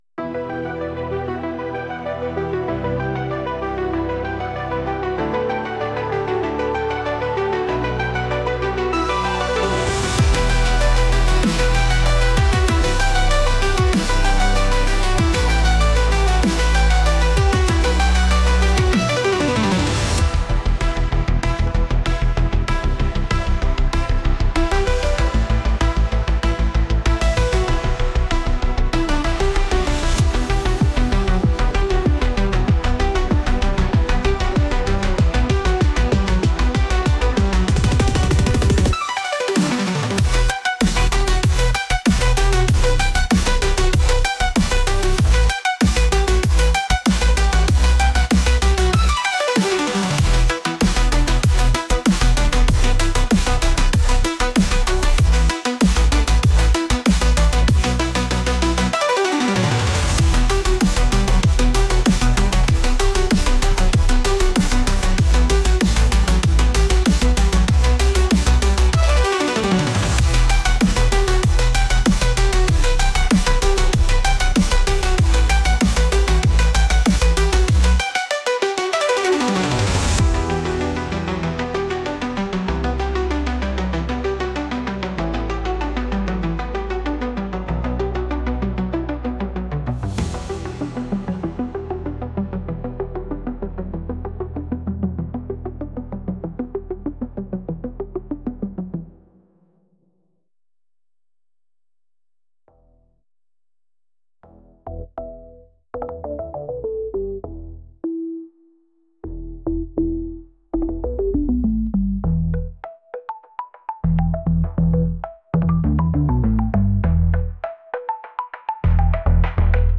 エレクトリックで問題解決に向かうような曲です。